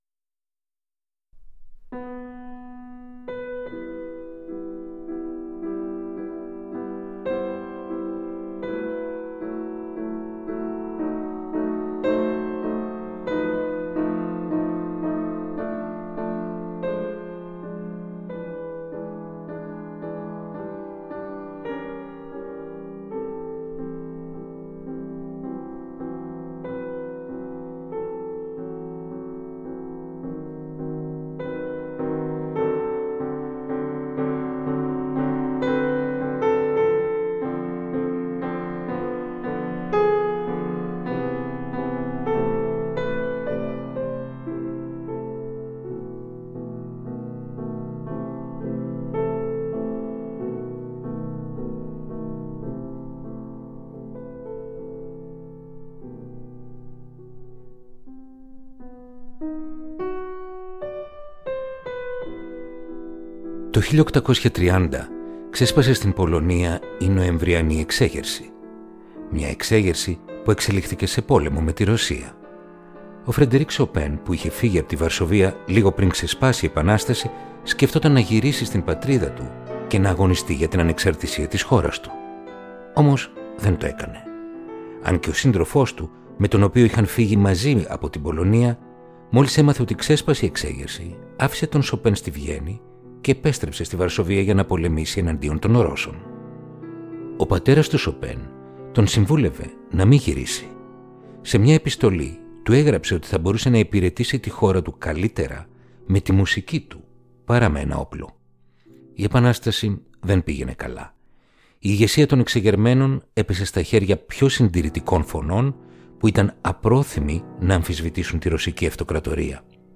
Ρομαντικά κοντσέρτα για πιάνο – Επεισόδιο 17ο